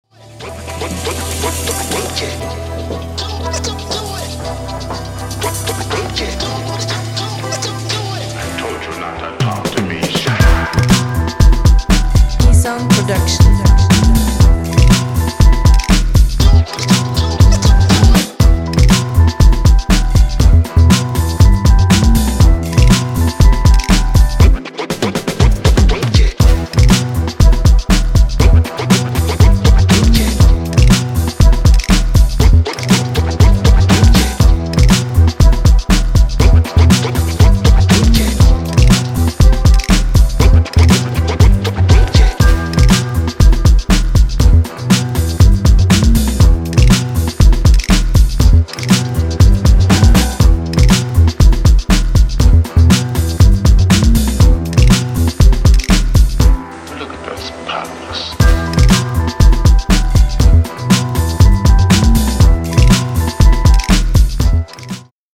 Electrofunk, Bass & Technobreaks VINYLs, CDs, & Merch. http